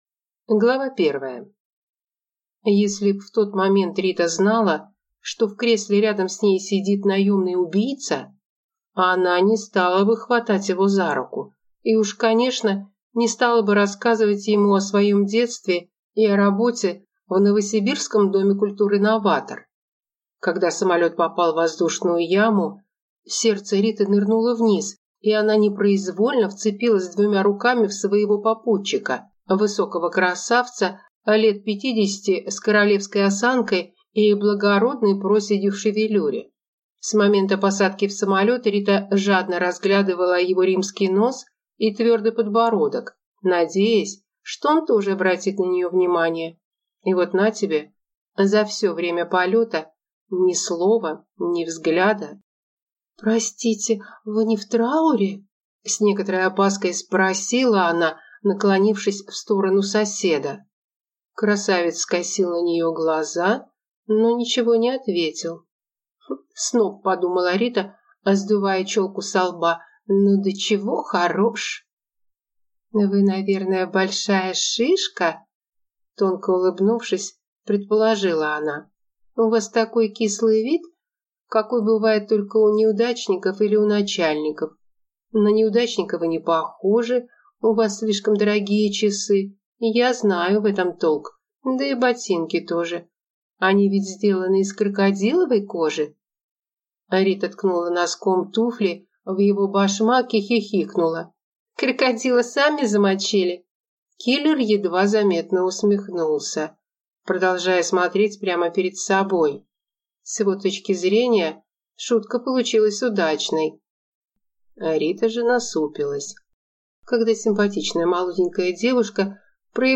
Аудиокнига Гарем покойников | Библиотека аудиокниг